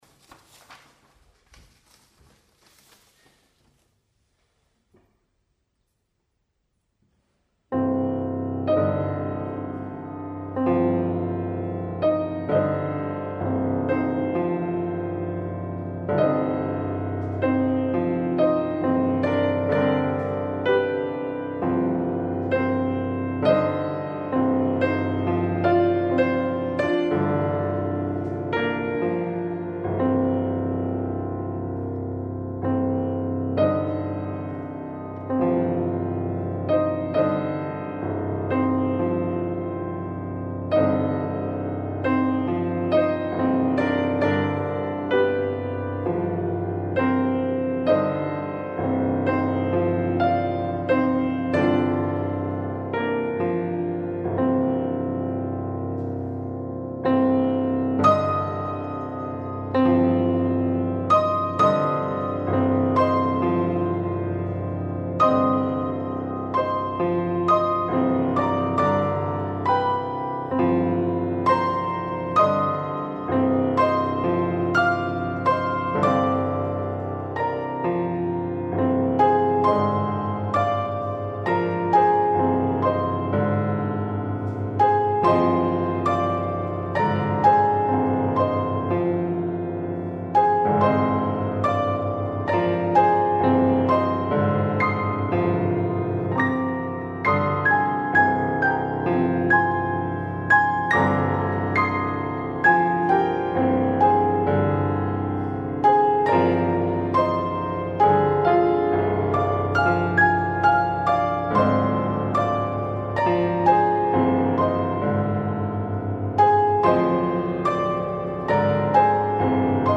for Two Pianos